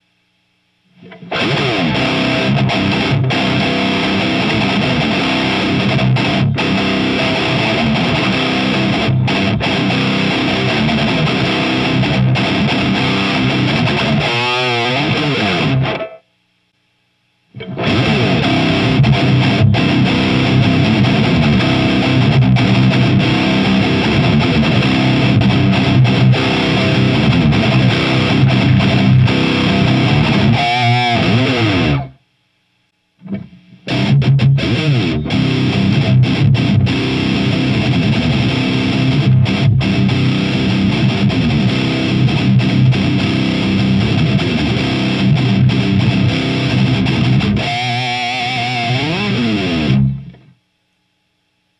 EMG81です。
以前の歪み。
LEED2→ディープオン（低音域強調）→トーンシフト（中音域をカットでドンシャリ）